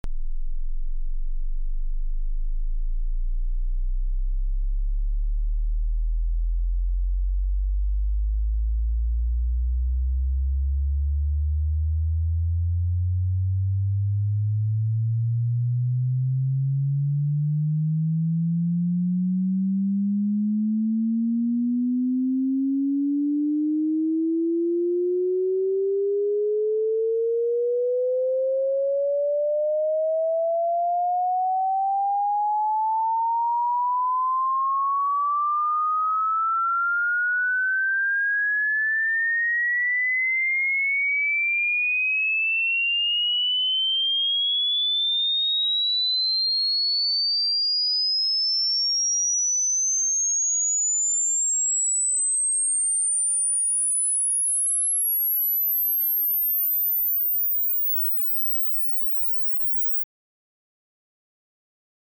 Test tone courtesy of M-audio
This is a test tone with range from 20Hz to 20 KHz.
20Hzto20KHzsweep.mp3